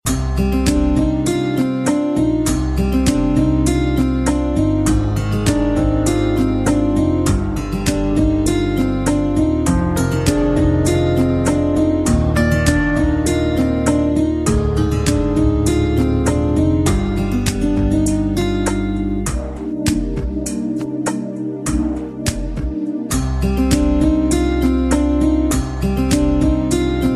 File Type : Bollywood ringtones